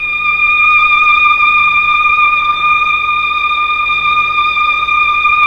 Index of /90_sSampleCDs/Roland LCDP09 Keys of the 60s and 70s 1/STR_Melo.Strings/STR_Tron Strings